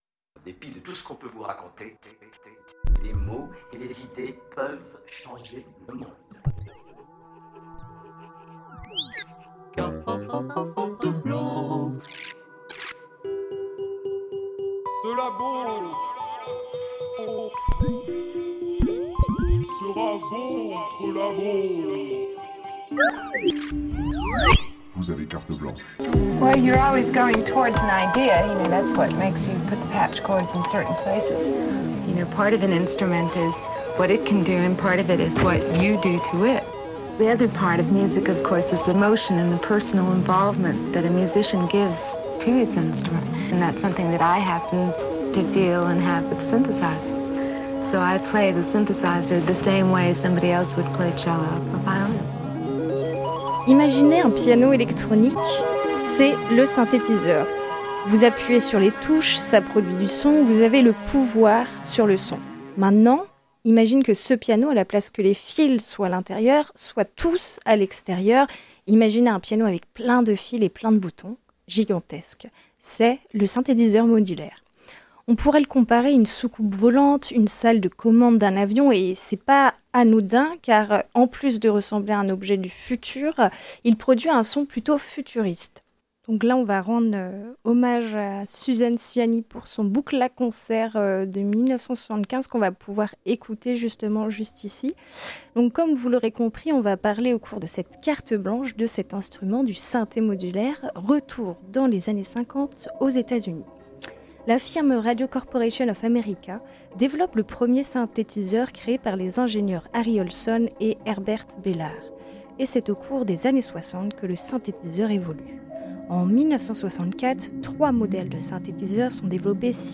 Une discussion autour du modulaire, de sa musique, de ses inspirations, sous le soleil de Brest, en face du pont de Recouvrance.